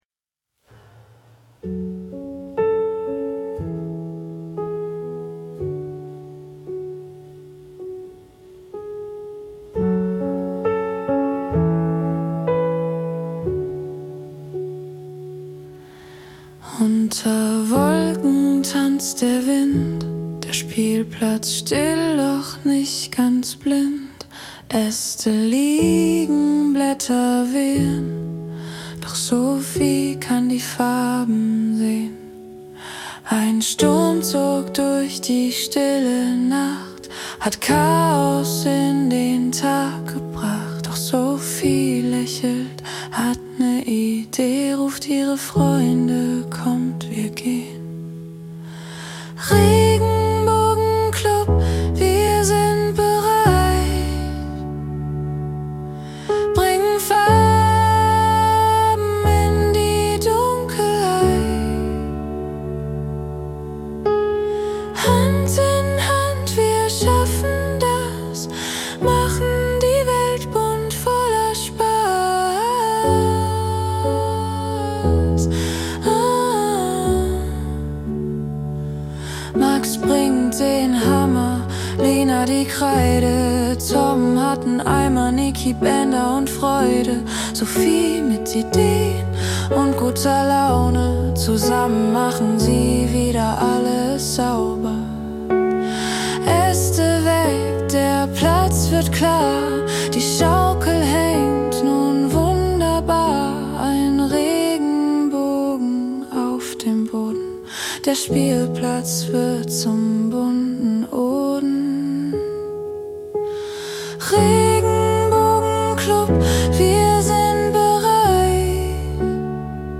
Das Schlaflied zur Geschichte